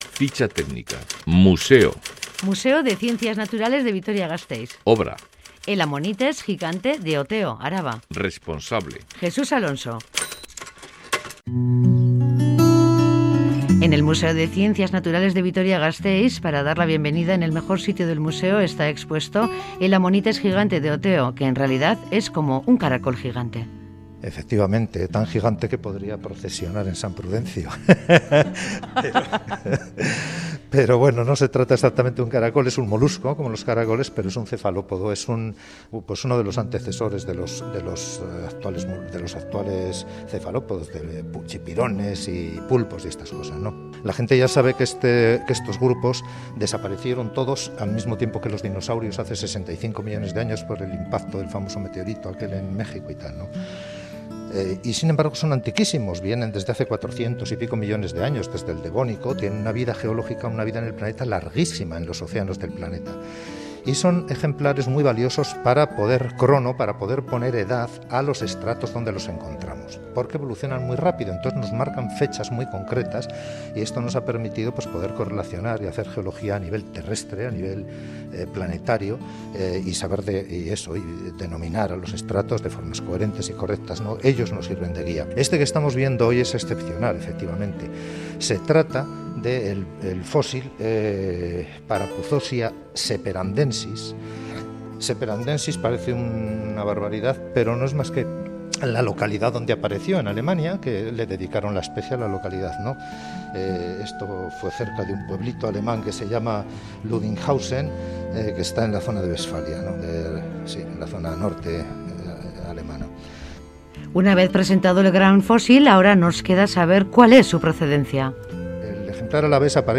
En el Museo de Ciencias Naturales de Gasteiz